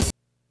hihat.wav